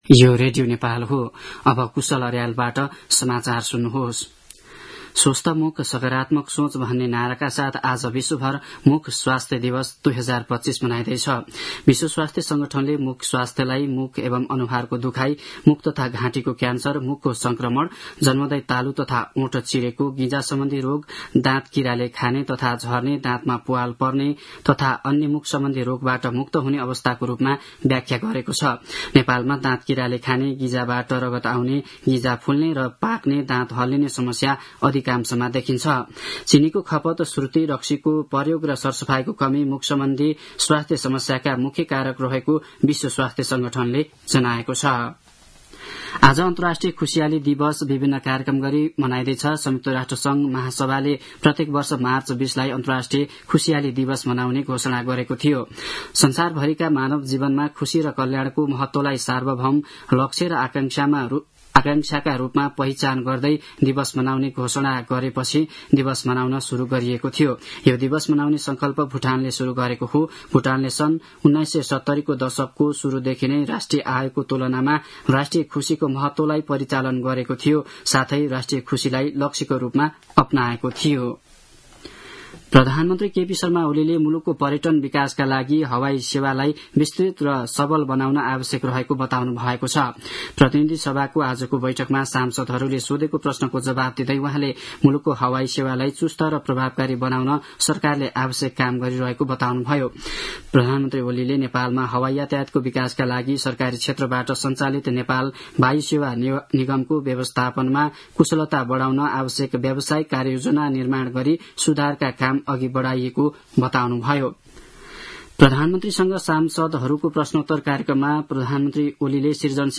दिउँसो ४ बजेको नेपाली समाचार : ७ चैत , २०८१
4-pm-news-1-5.mp3